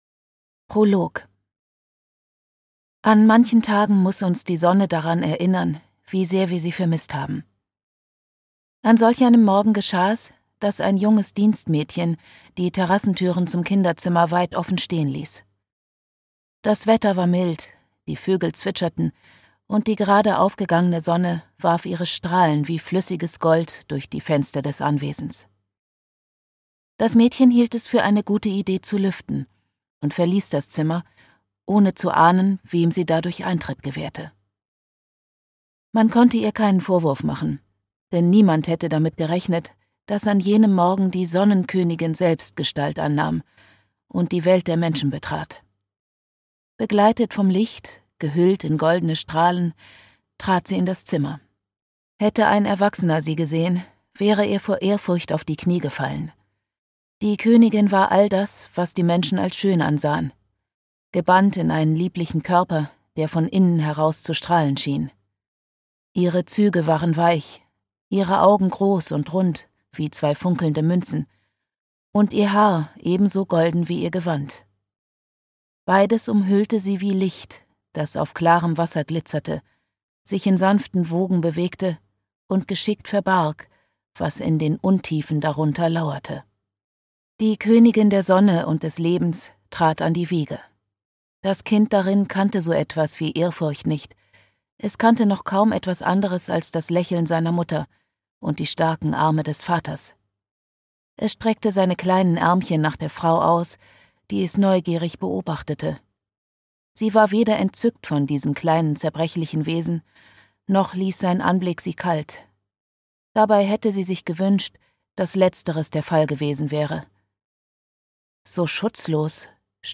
Die Nacht der fallenden Sterne - Jennifer Alice Jager - Hörbuch